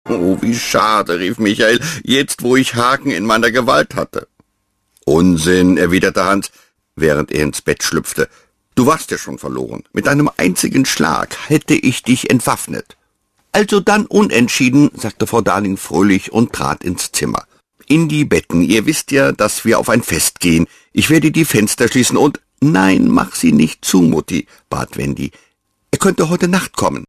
deutscher Sprecher
berlinerisch
Sprechprobe: Industrie (Muttersprache):
german voice over talent